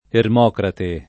[ erm 0 krate ]